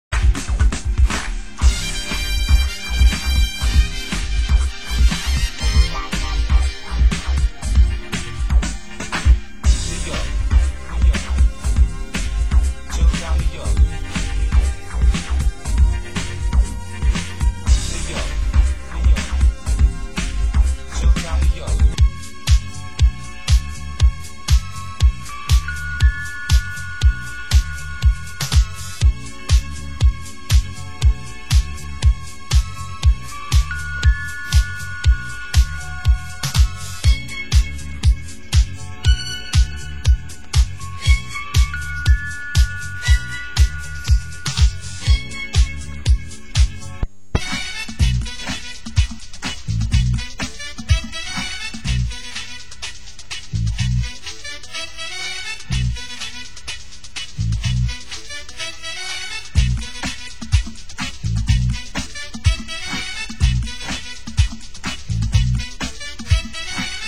Genre: US House